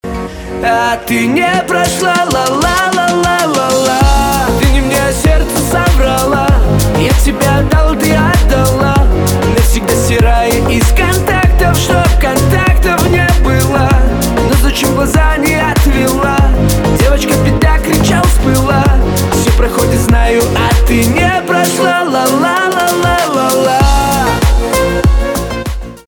кавказские
грустные
битовые